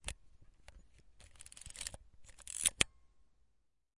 单反相机+闪光灯 " 宾得K1000快门01
描述：按下Pentax K1000 SLR相机上的快门释放按钮。 用Tascam DR40录制。
标签： 拍摄图片 相机咔嚓咔嚓单反-camera 照相机 快门 PENTAX-K1000 SLR 说明书 摄影 照片 K1000 PENTAX 图片
声道立体声